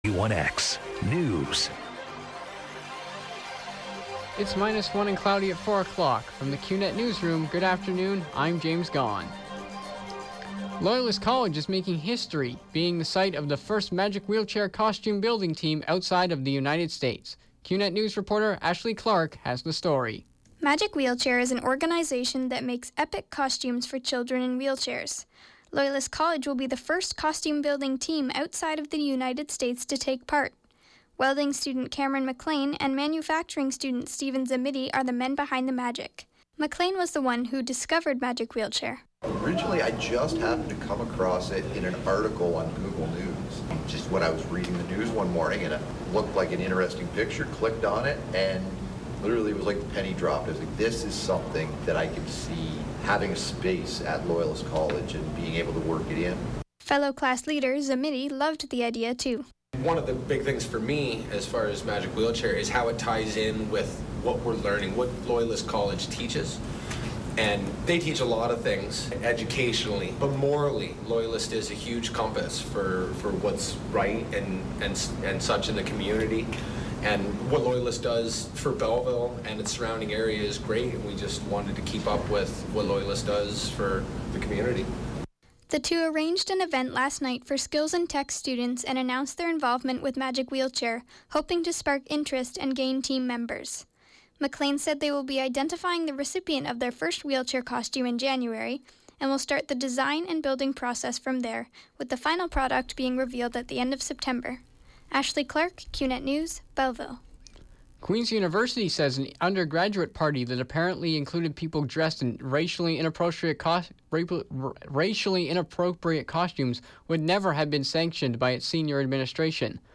91X Newscast- Wednesday, Nov. 23, 2016, 4 p.m.